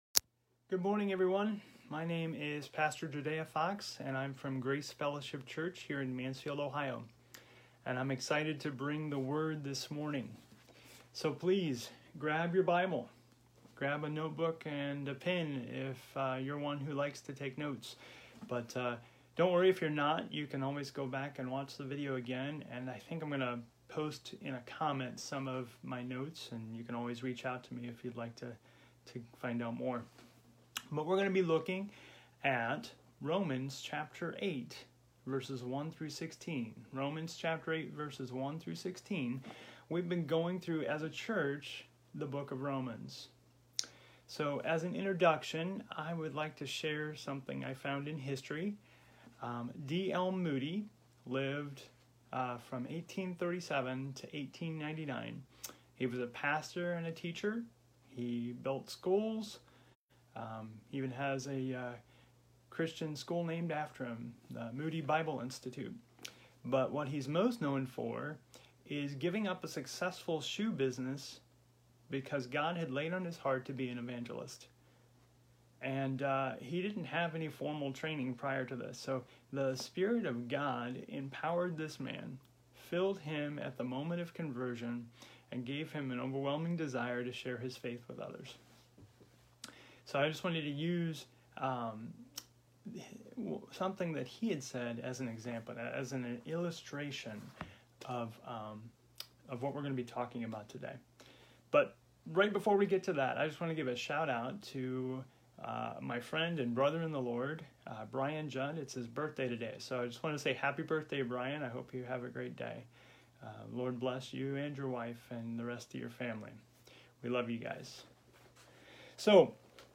Our video message was recorded on Facebook Live.